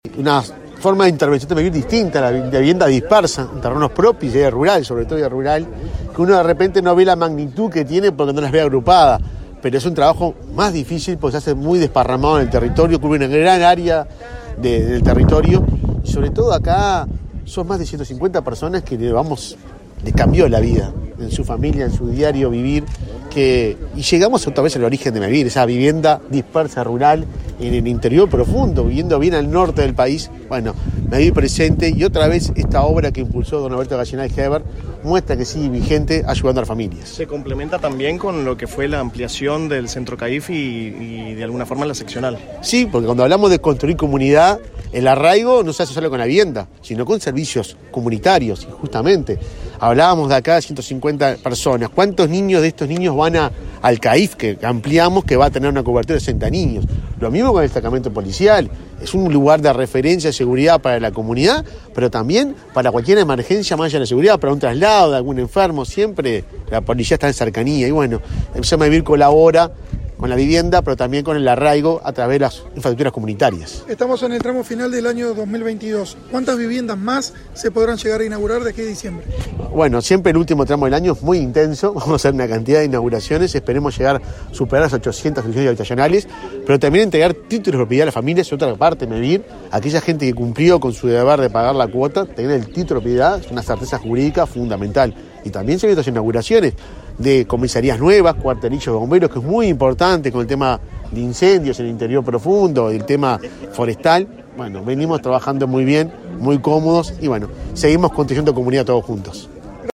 Declaraciones del presidente de Mevir, Juan Pablo Delgado
Declaraciones del presidente de Mevir, Juan Pablo Delgado 07/10/2022 Compartir Facebook X Copiar enlace WhatsApp LinkedIn El presidente de Mevir, Juan Pablo Delgado, y la ministra de Vivienda y Ordenamiento Territorial, Irene Moreira, participaron en el acto de entrega de 39 viviendas refaccionadas en el pueblo Sequeira, departamento de Artigas. Antes, Delgado dialogó con la prensa.